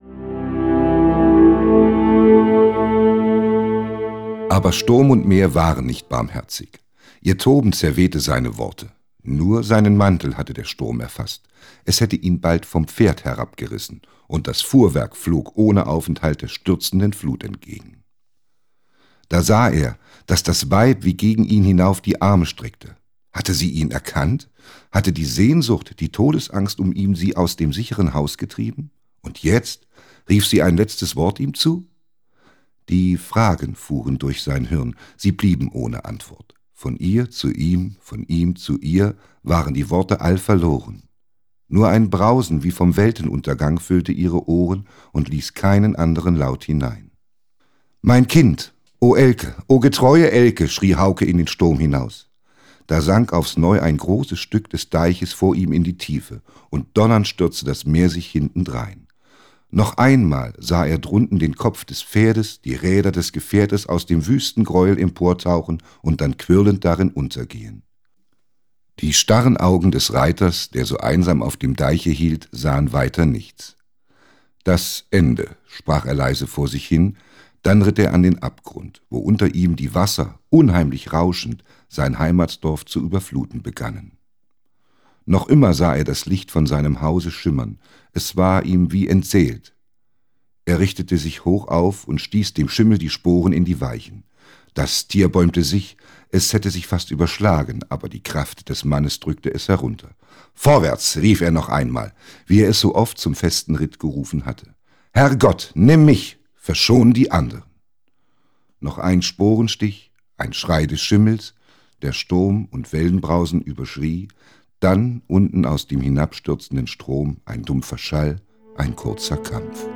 – Theodor Storm: Der Schimmelreiter (Gesprochen:)